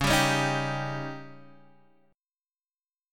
Dbsus2b5 chord